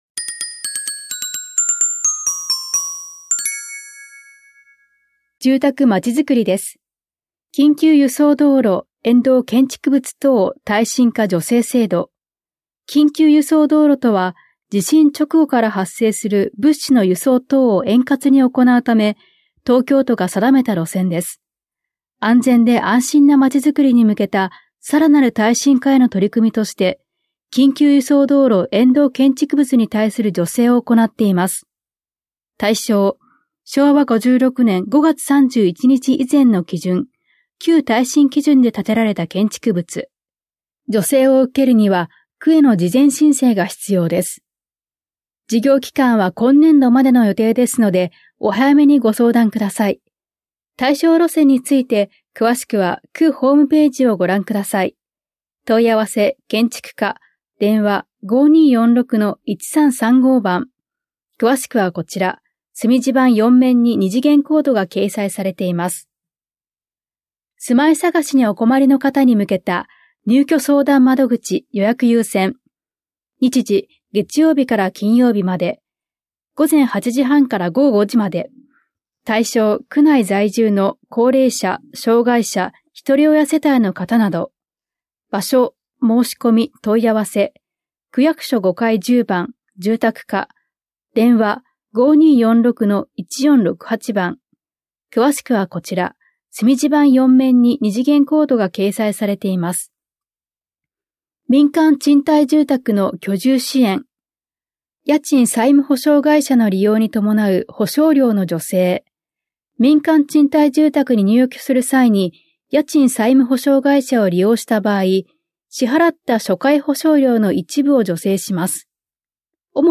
広報「たいとう」令和7年5月5日号の音声読み上げデータです。